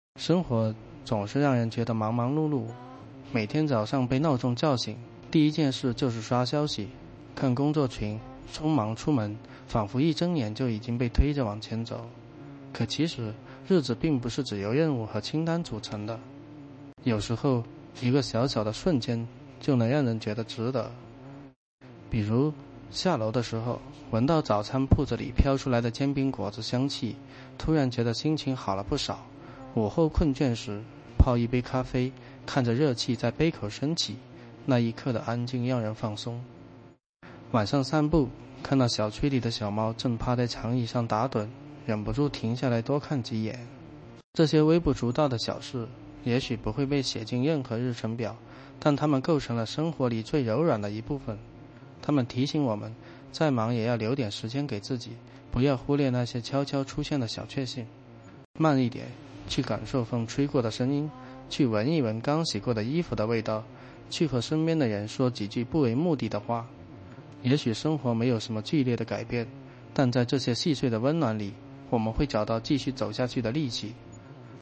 MegaTTS 3是字节跳动团队开发的一个文本转语音（TTS）模型。
它最核心的功能是声音克隆，只需要一段几秒钟的简短音频，就能模仿这个人的声音说出任何话。
为了让生成的声音听起来更自然，不像机器人，它还用了一种叫做“稀疏对齐”的技术来处理停顿和语调，使得说话的节奏感更像真人。